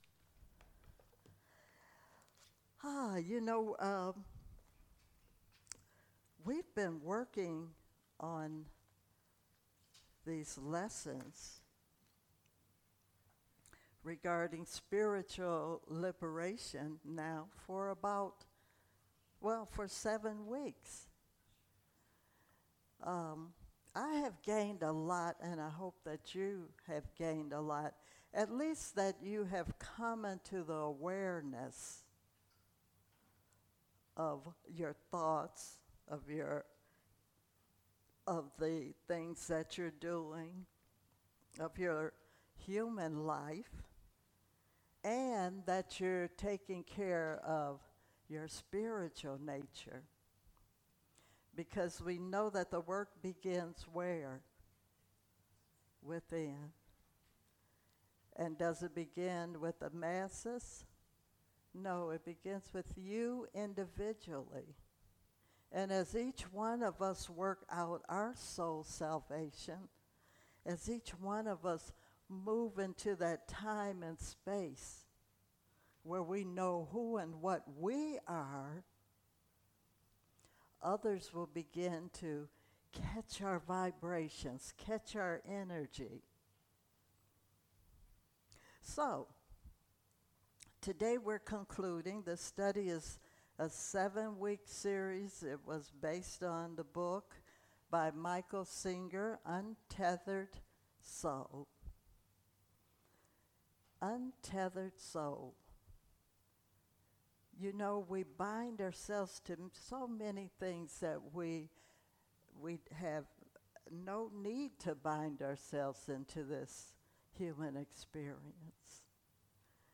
Spiritual Leader Series: Sermons 2022 Date